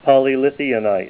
Help on Name Pronunciation: Name Pronunciation: Polylithionite + Pronunciation